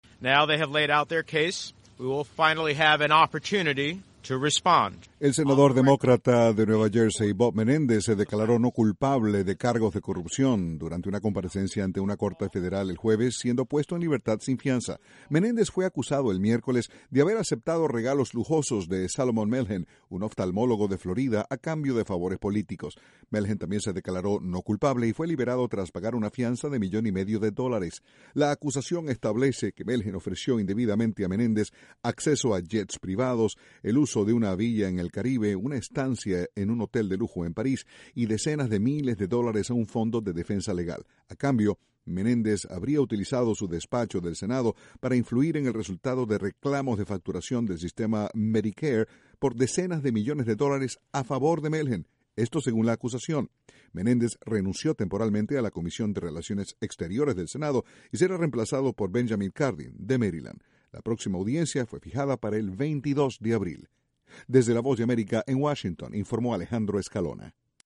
Un senador estadounidense se declaró “no culpable” en un caso por presunta corrupción. Desde la Voz de América, en Washington